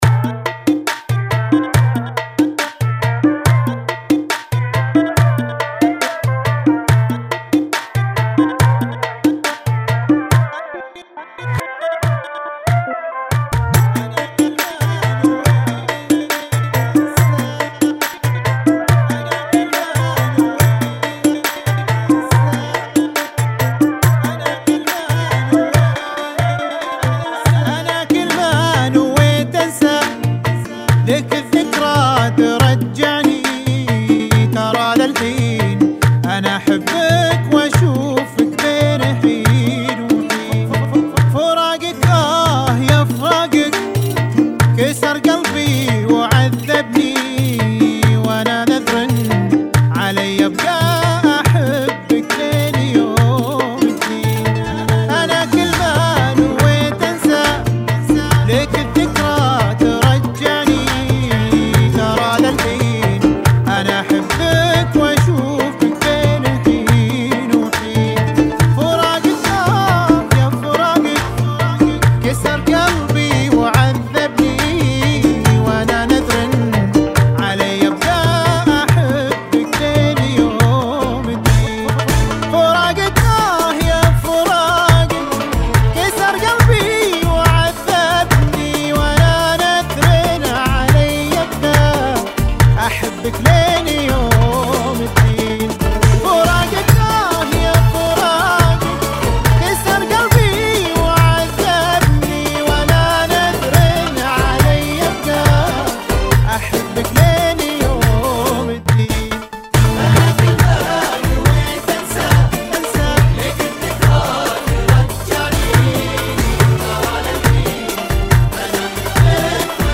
[ 70 Bpm ]